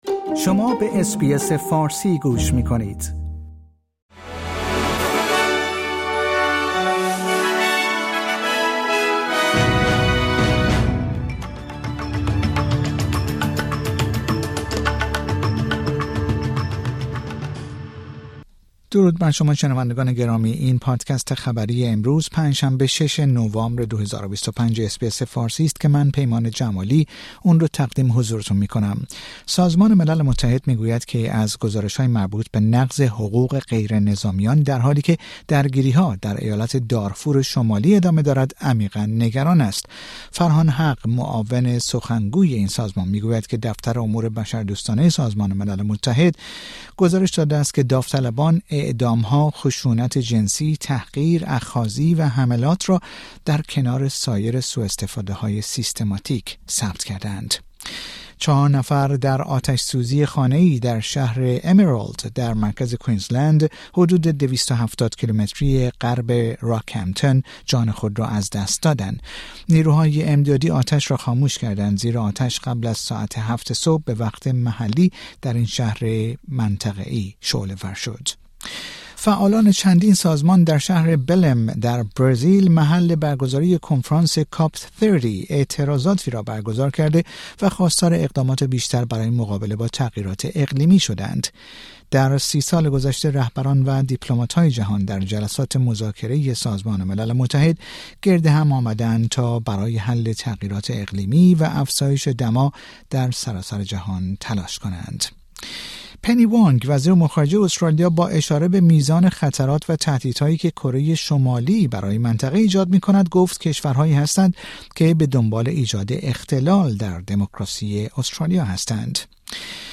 در این پادکست خبری مهمترین اخبار روز پنج شنبه ۶ نوامبر ارائه شده است.